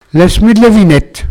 Enquête Arexcpo en Vendée
locutions vernaculaires